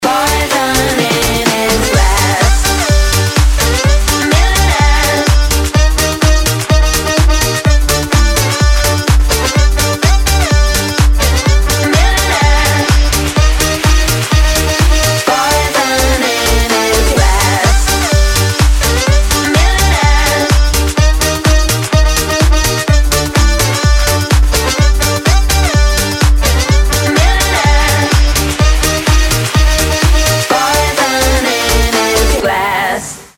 • Качество: 192, Stereo
Прикольная и заводная музыка на мобильный